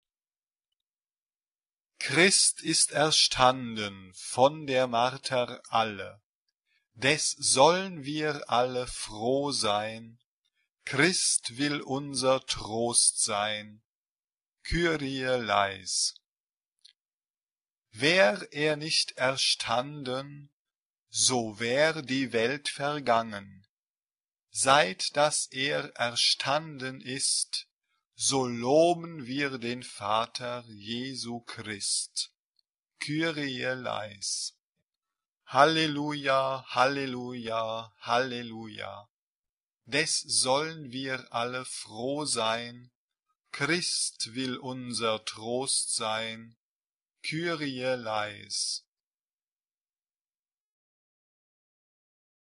geistlich ; Chor
SAB (3 gemischter Chor Stimmen )
Tonart(en): a-moll